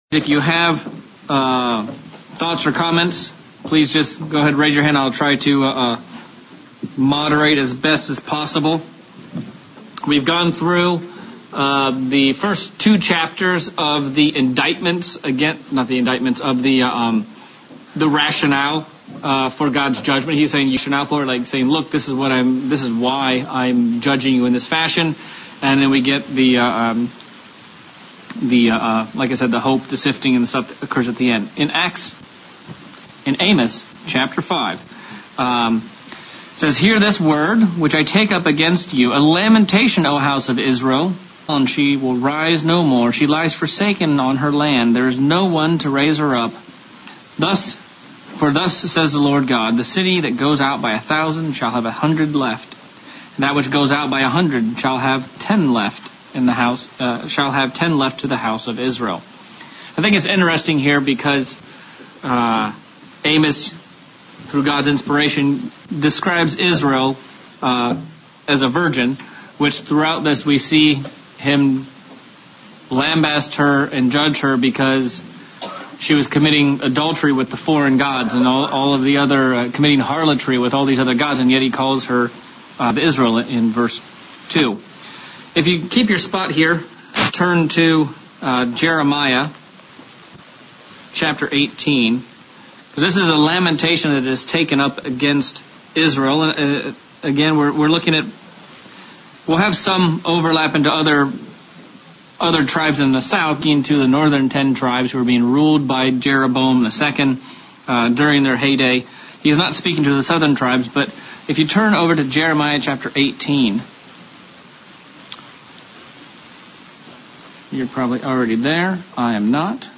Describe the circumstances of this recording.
Given in Central Illinois